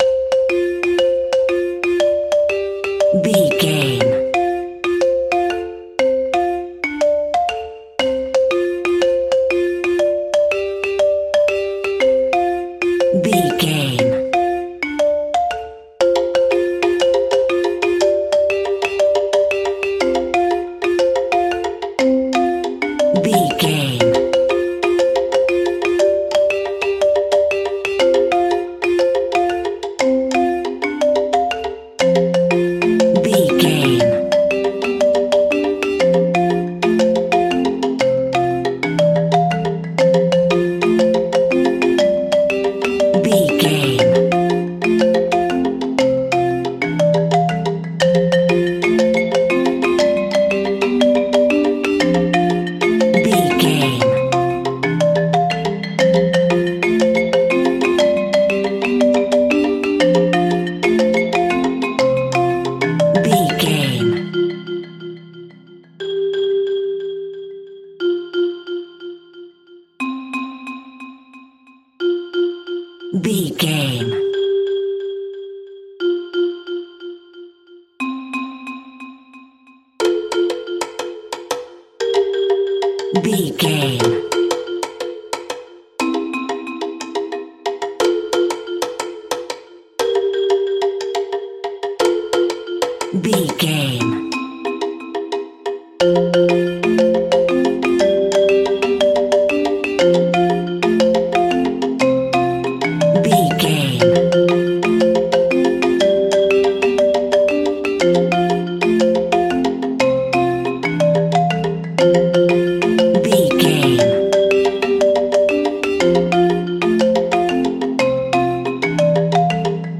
Uplifting
Ionian/Major
Fast
childrens music
drums
bass guitar
electric guitar
piano
hammond organ